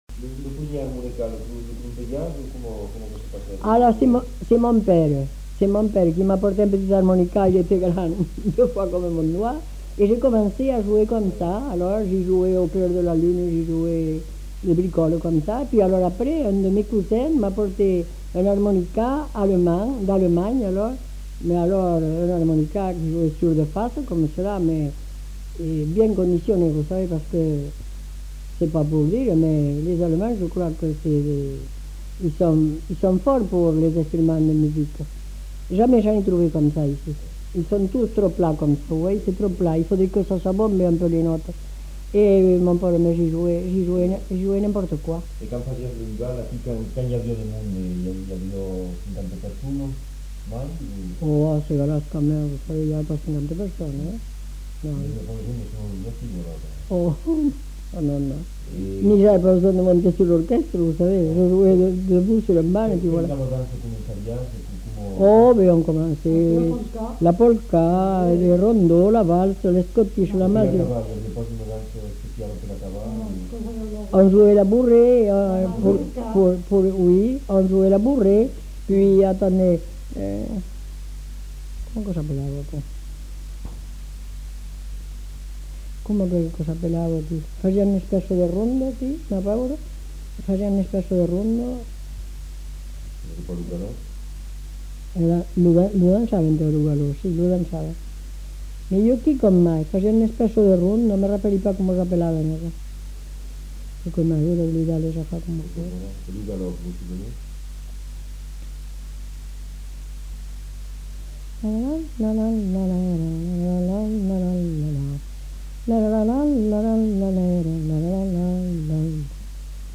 Aire culturelle : Haut-Agenais
Lieu : Cancon
Genre : récit de vie